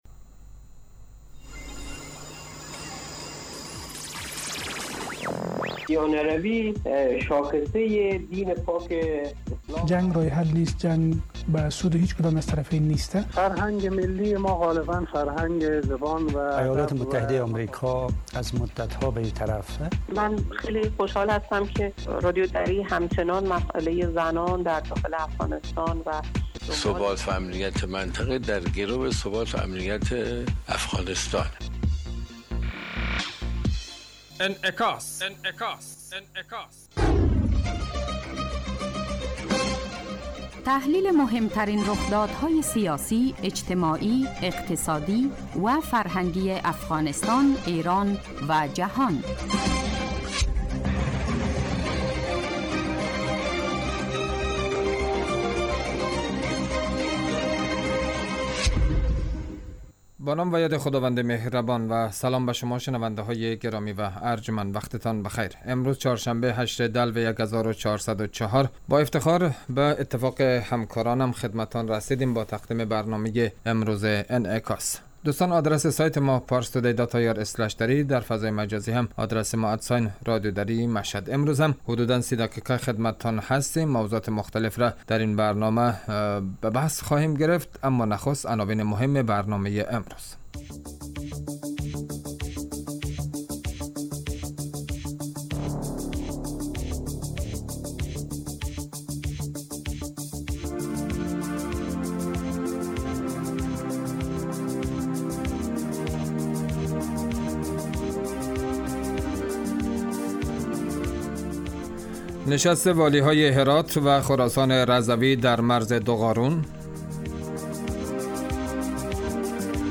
برنامه انعکاس به مدت 30 دقیقه هر روز در ساعت 12:30 ظهر (به وقت افغانستان) بصورت زنده پخش می شود.